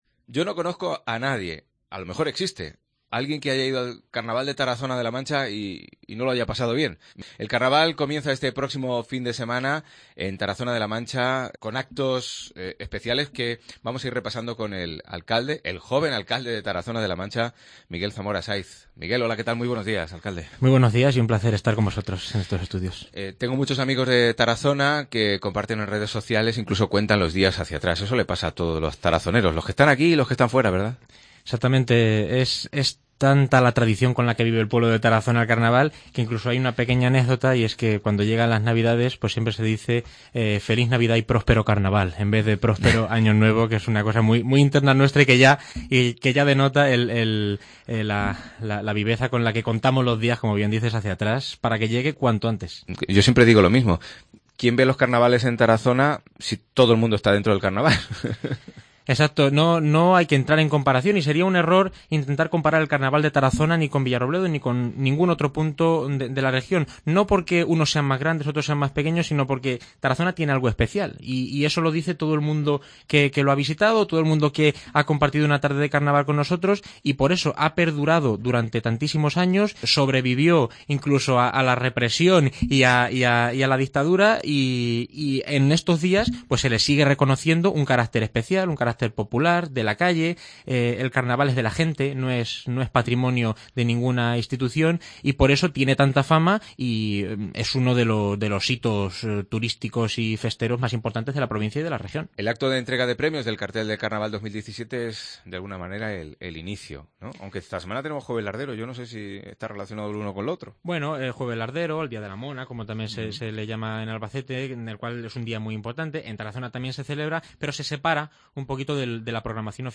El joven alcalde de Tarazona de la Mancha, Miguel Zamora (27 años) viene a Mediodía COPE para hablarnos del Carnaval, el más popular de la provincia, pero también de otros proyectos como el de su nuevo Ayuntamiento.